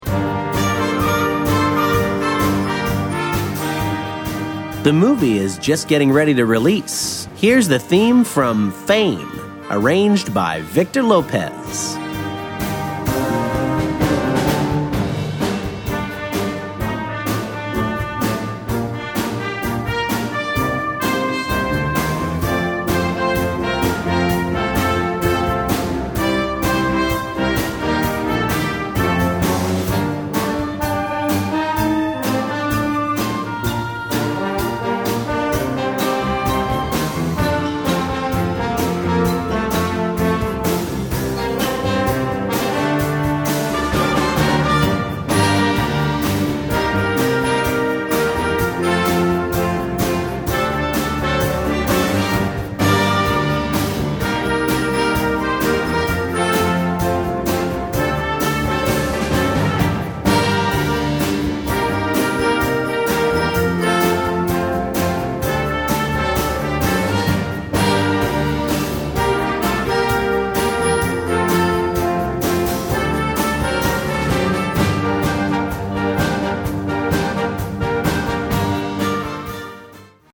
Werk für Jugendblasorchester
Besetzung: Blasorchester